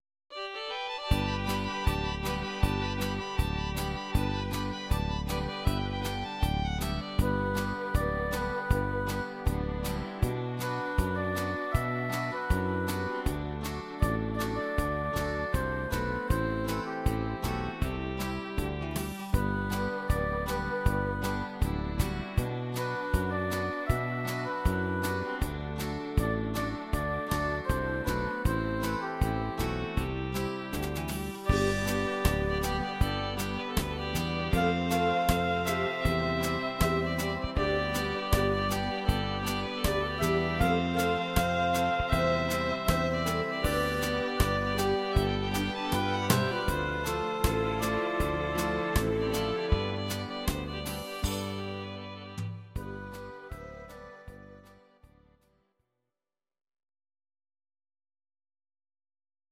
Audio Recordings based on Midi-files
Our Suggestions, Country, Traditional/Folk, 1990s